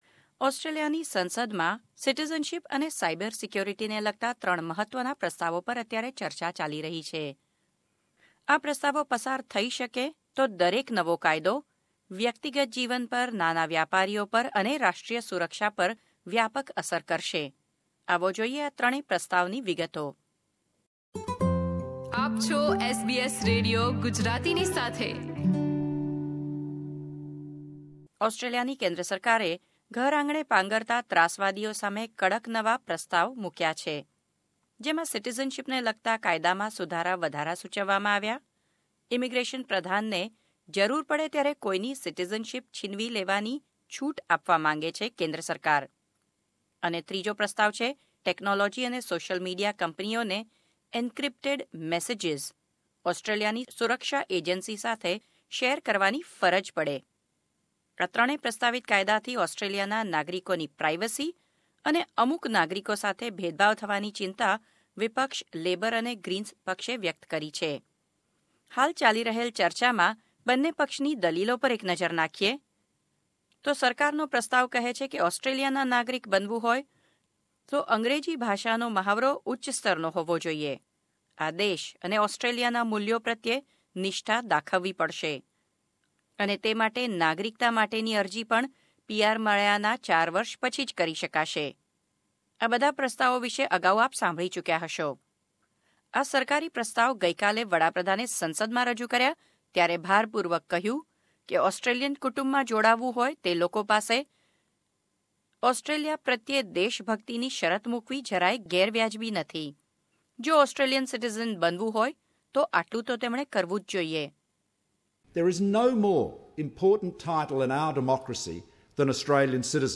વિગતવાર અહેવાલ